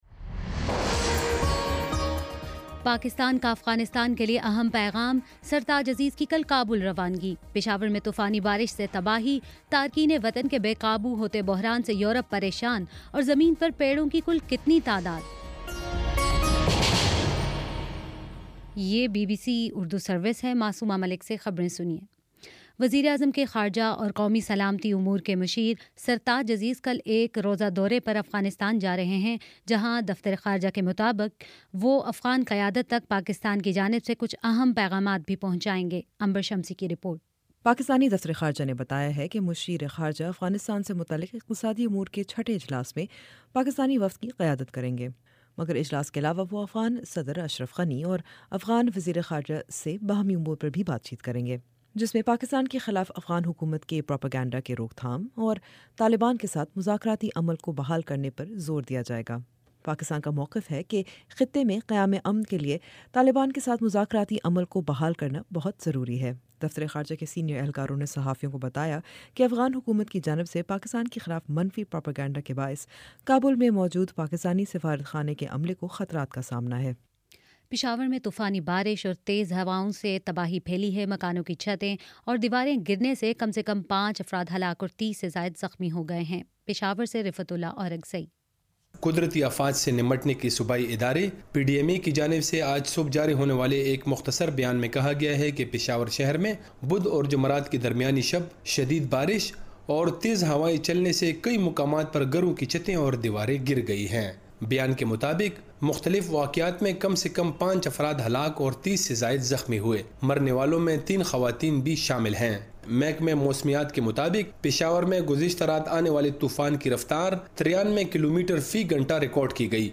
ستمبر 3: شام چھ بجے کا نیوز بُلیٹن